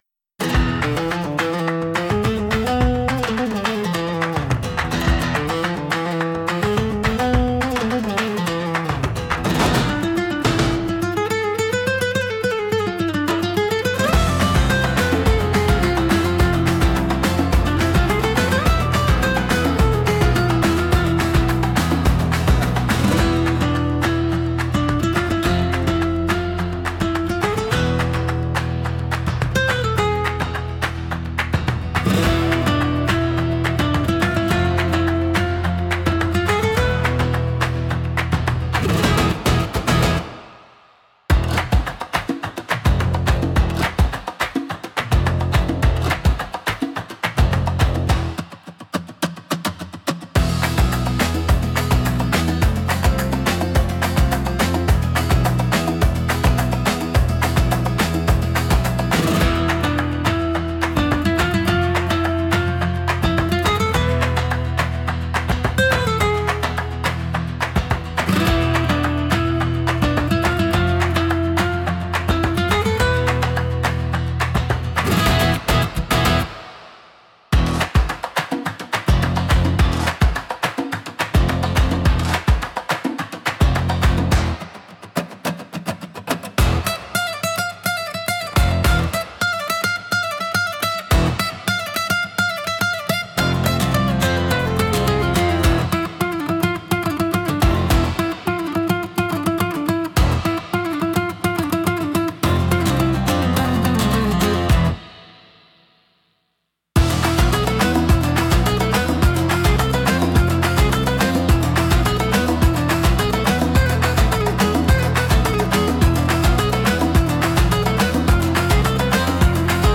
Instrumental / 歌なし
🌍 Passion, rhythm, and fire.
combines bold guitar, powerful rhythms, and dramatic energy,
情熱的なギター、力強いリズム、鋭いパルマ（手拍子）が織りなす、迫力満点のフラメンコ音楽。